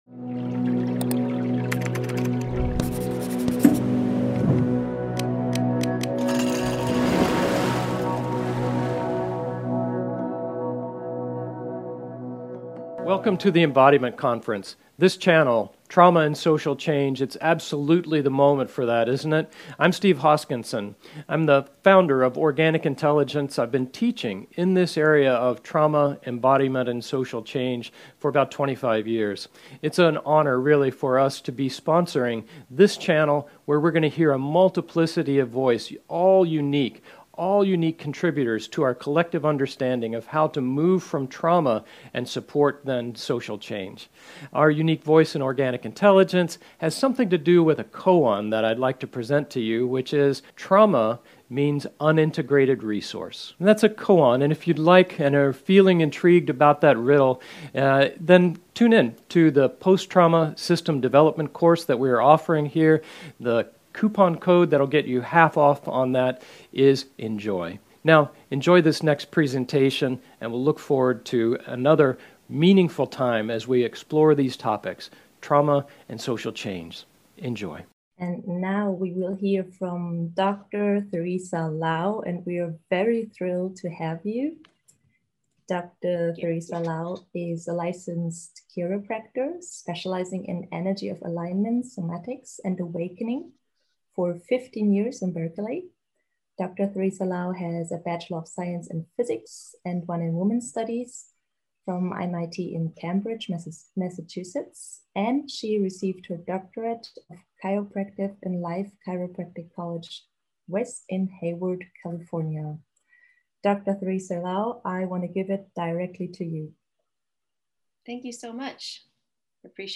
It's time to come out of the isolation of personal, individual healing and define the new rules for self-care. This workshop is for people who are intrigued by somatic healing practice as an integral component of finding liberation for ourselves and our communities.